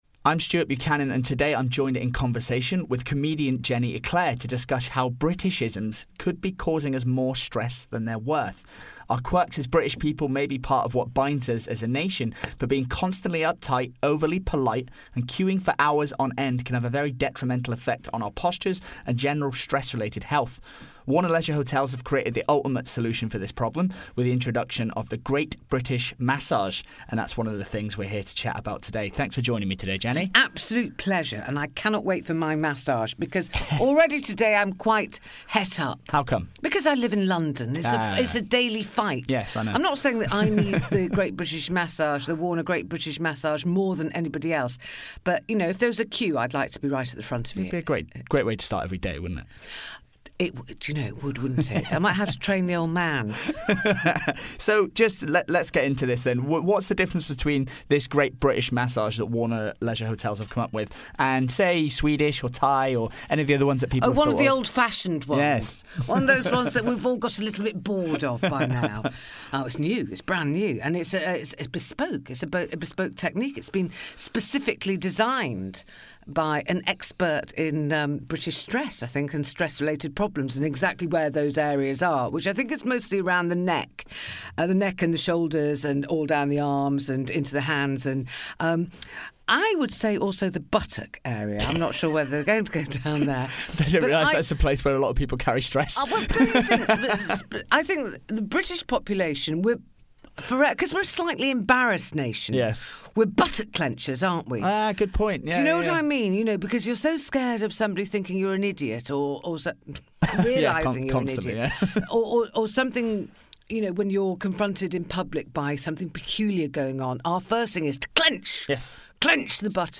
We’re joined In Conversation With comedian and social commentator, Jenny Éclair to discuss how British-isms could be causing us more stress than they’re worth.